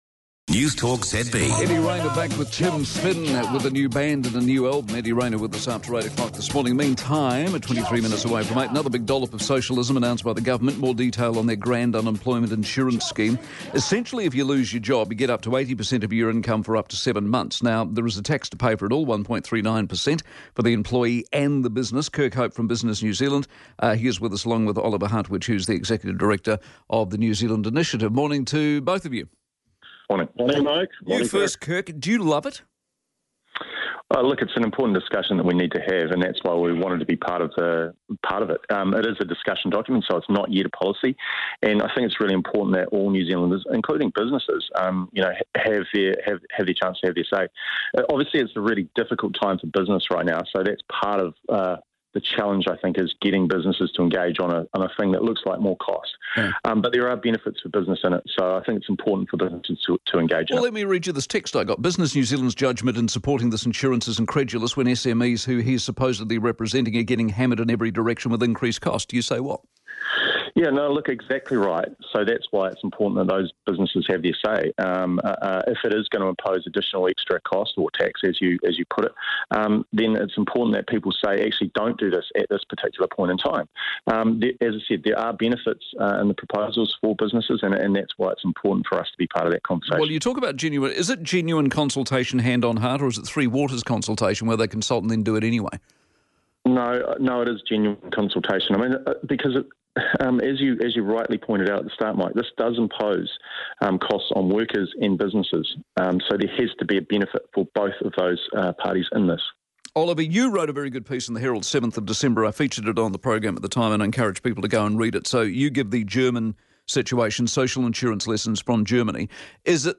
Newstalk ZB - Mike Hosking Breakfast